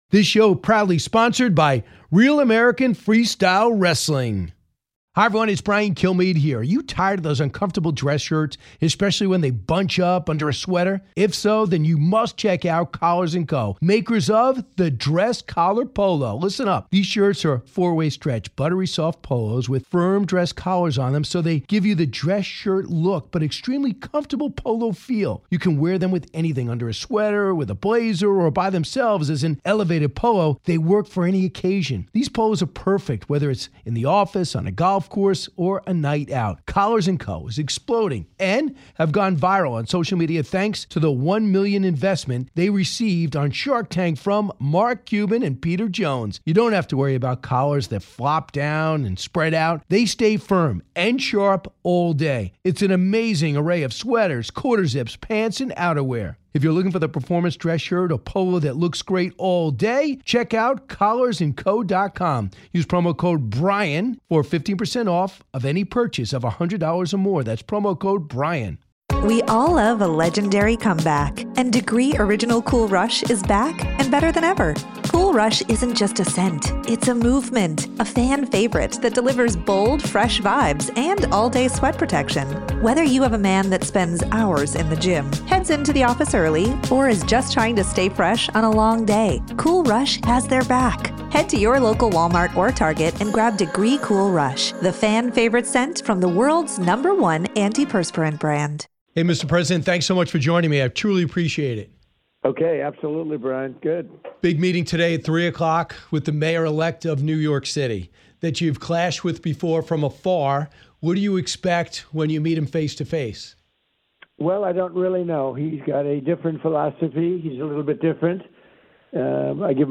Full Interview: President Donald Trump on meeting with Zohran Mamdani